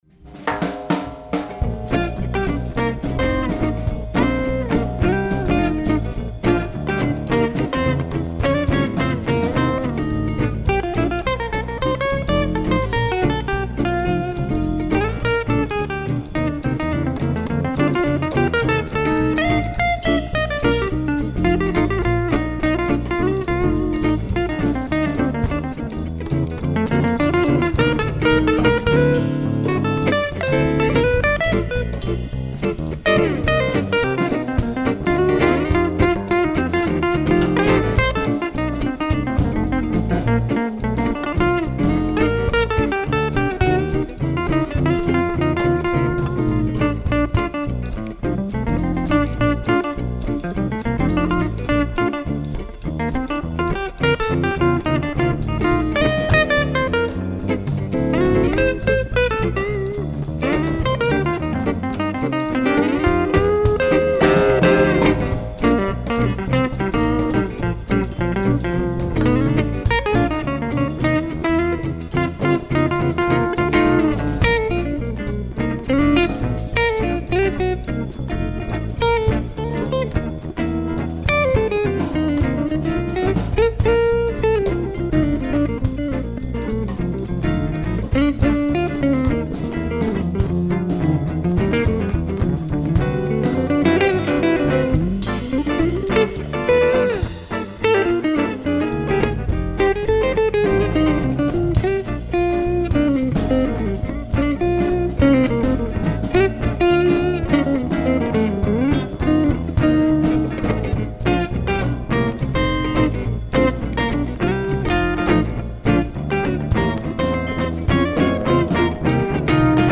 le répertoire jazz-blues (avec batterie) :
Enregisté en concert à la Fête de la musique le